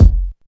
Bass Drum One Shot F# Key 210.wav
Royality free steel kick drum sound tuned to the F# note. Loudest frequency: 115Hz
bass-drum-one-shot-f-sharp-key-210-Wb2.ogg